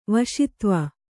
♪ vaśitva